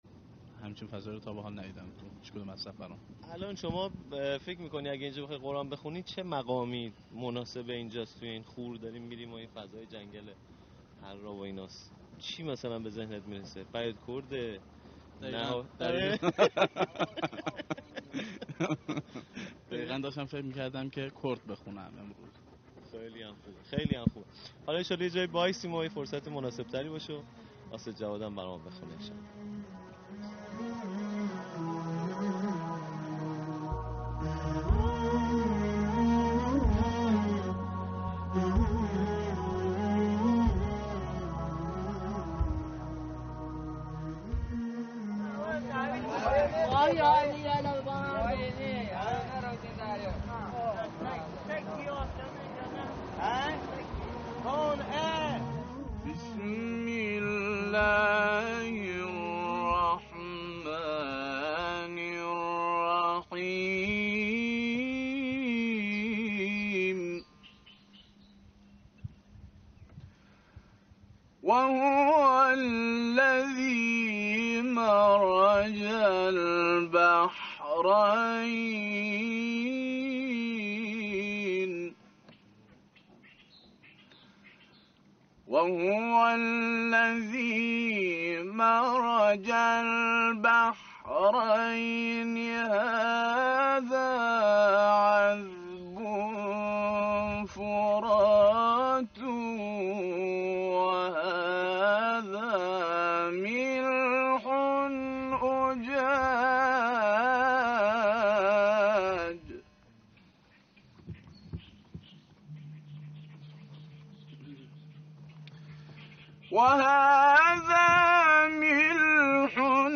نغمات صوتی از قاریان ممتاز کشور
گروه شبکه اجتماعی: فرازهای صوتی از قاریان ممتاز و تعدادی از قاریان بین‌المللی کشورمان را می‌شنوید.